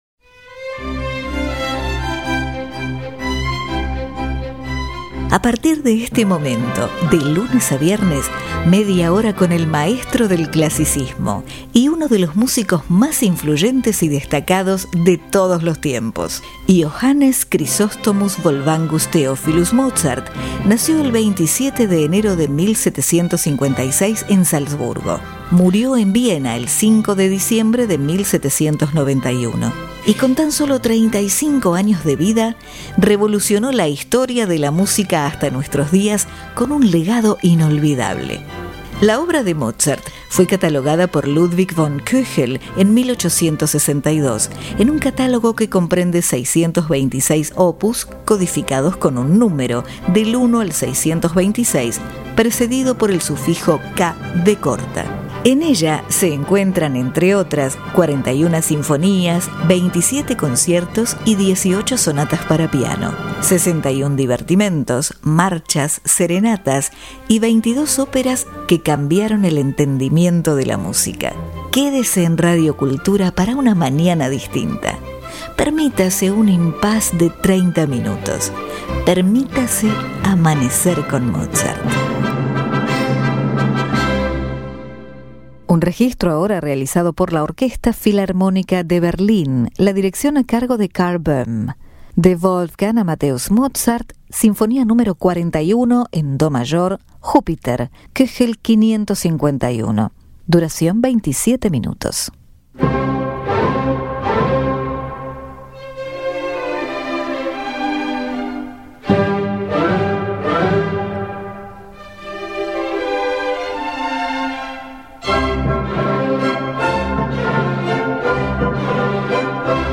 Sinfonia Nº 41 En Do Mayor
Orquesta Filarmonica De Berlin Karl Böhm